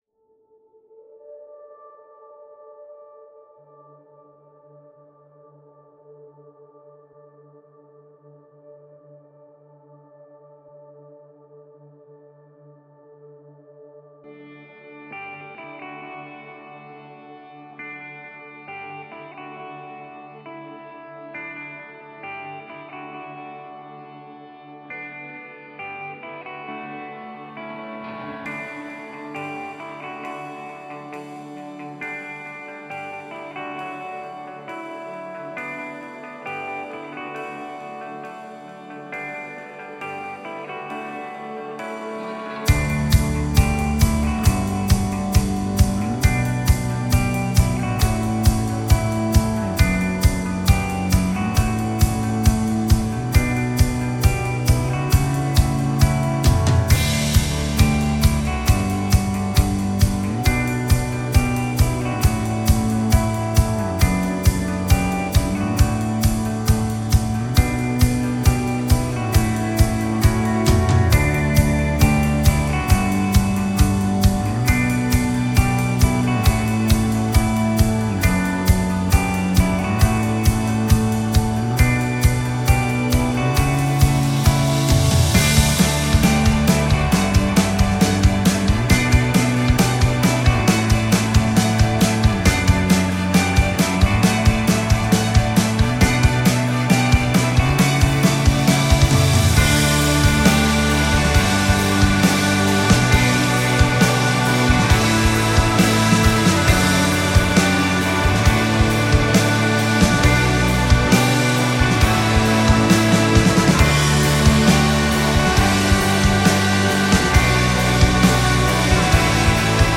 موسیقی اینسترومنتال